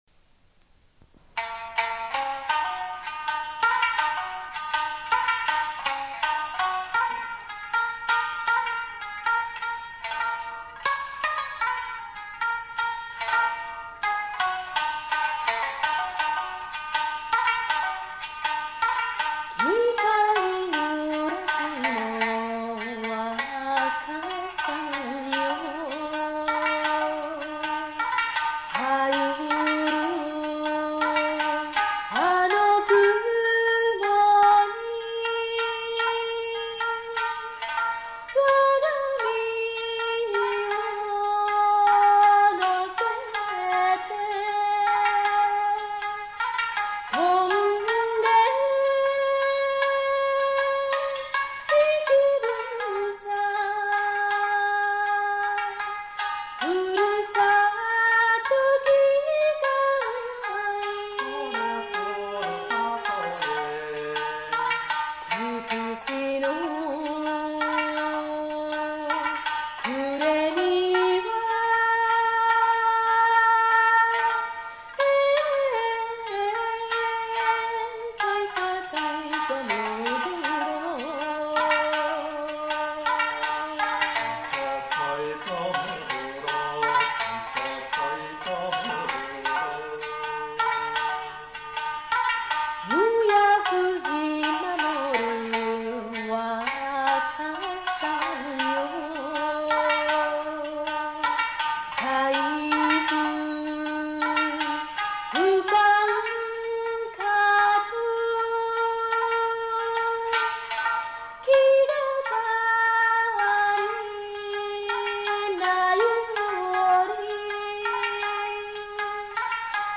三味線